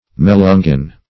Melungeon \Me*lun"geon\, n. [Cf. F. m['e]langer to mix,